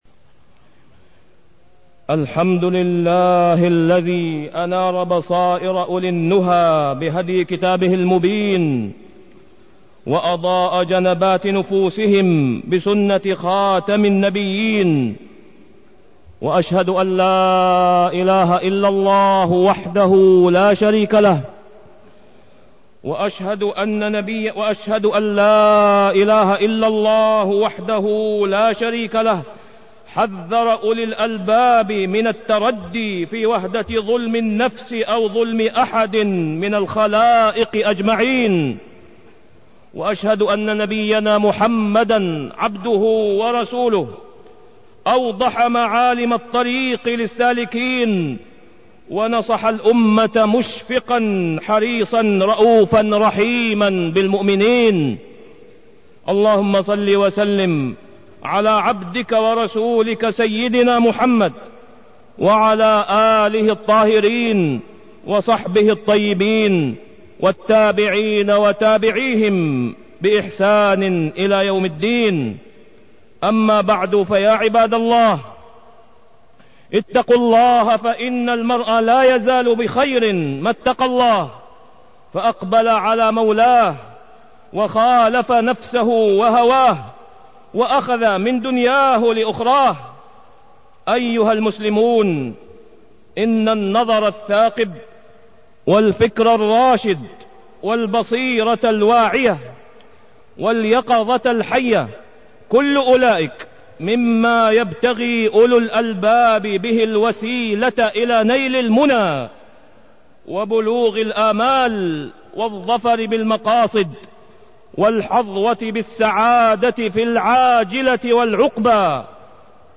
تاريخ النشر ١٩ محرم ١٤٢٢ هـ المكان: المسجد الحرام الشيخ: فضيلة الشيخ د. أسامة بن عبدالله خياط فضيلة الشيخ د. أسامة بن عبدالله خياط حقيقة المفلس